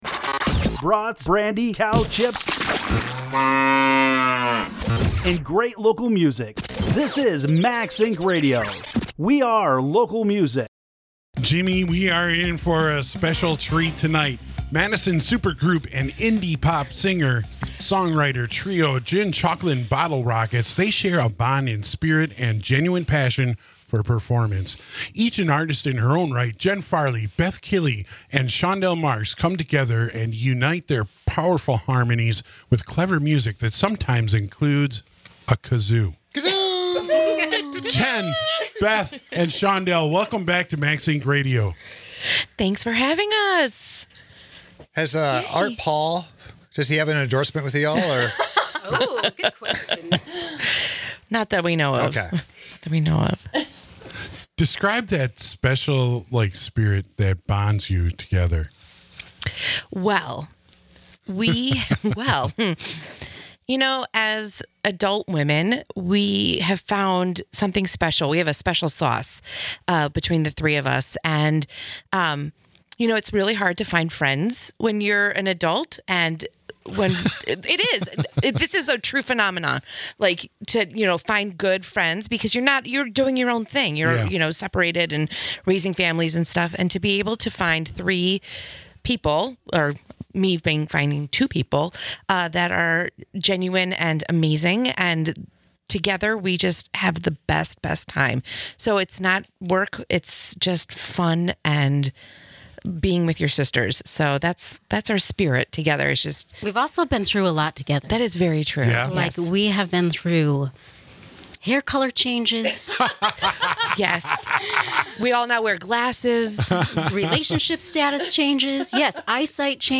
interview and performance